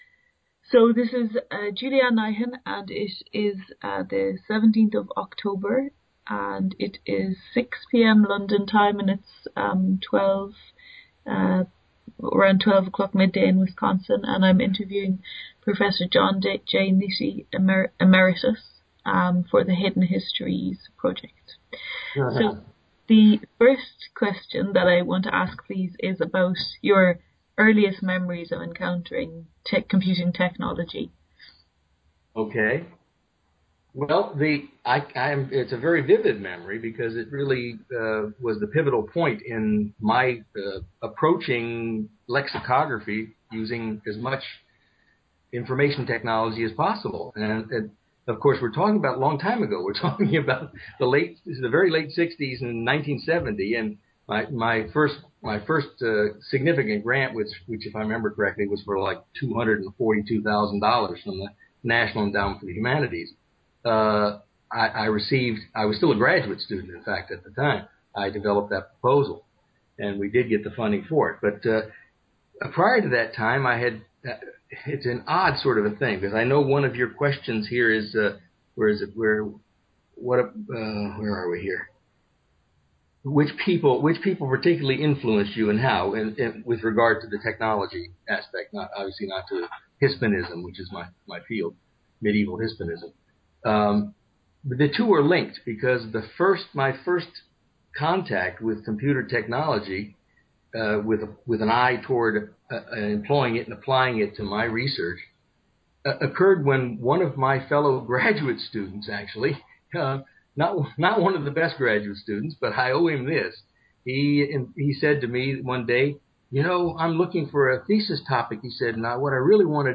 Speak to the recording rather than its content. Skype interview This oral history conversation was carried out via Skype on 17 October 2013 at 18:00 GMT.